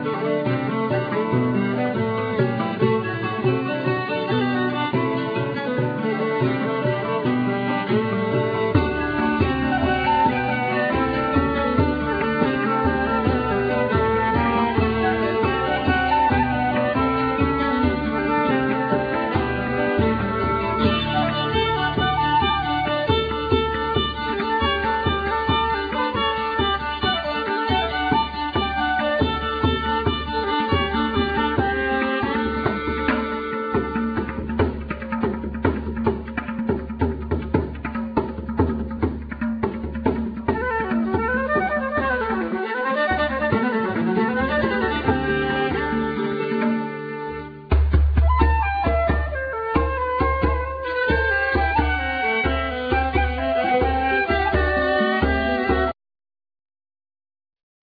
Guitar
Flute
Viola,Violin
Drums,Vibrapone,Cembalo,Piano